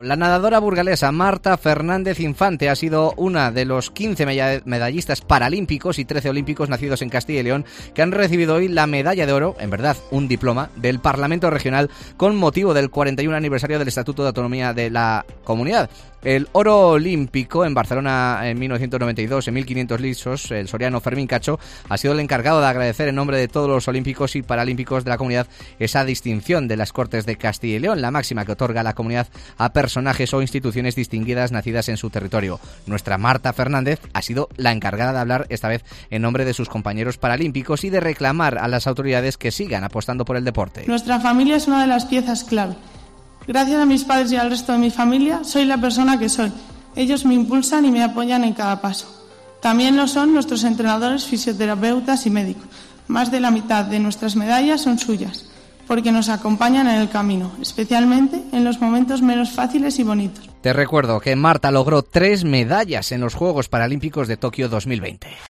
La nadadora paralímpica ha sido la encargada de hablar en nombre de sus compañeros y ha aprovechado para reclamar a las autoridades que sigan apostando por el deporte